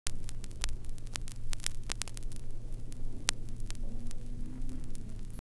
VINYL2    -R.wav